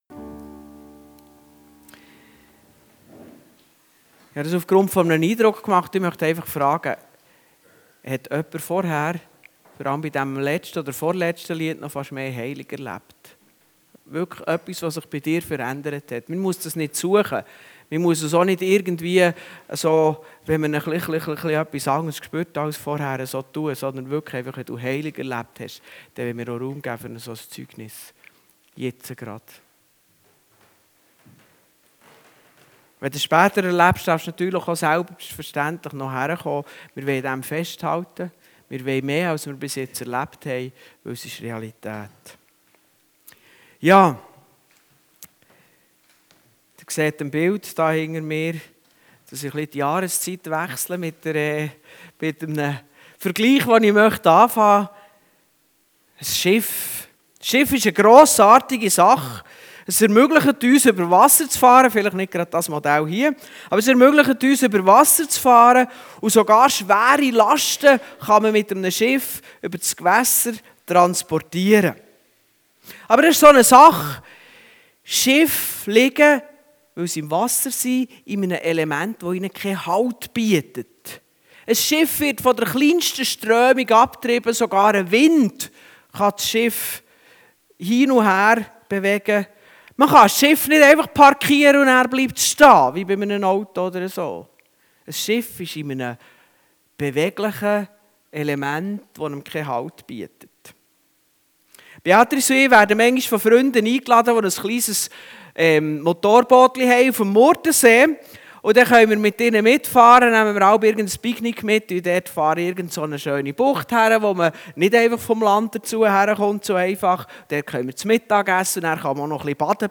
Passage: Römer 8, 31-39 Dienstart: Gottesdienst